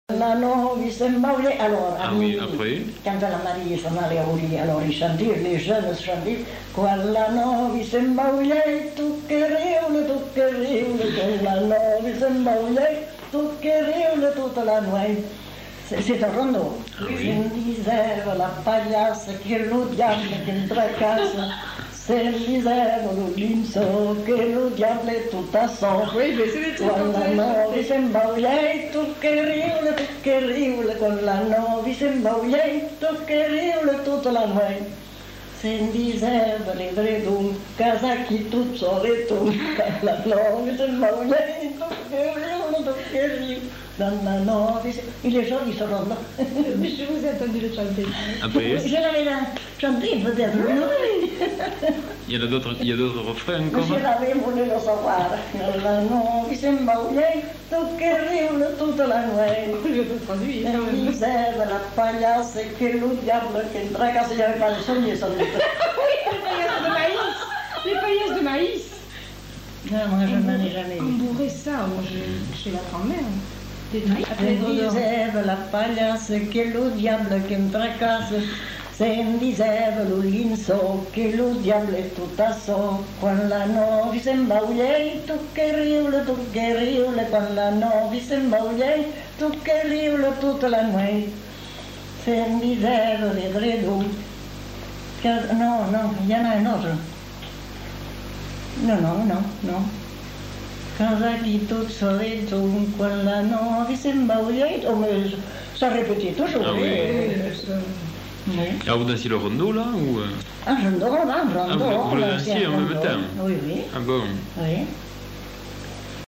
Lieu : Mont-de-Marsan
Genre : chant
Effectif : 1
Type de voix : voix de femme
Production du son : chanté
Danse : rondeau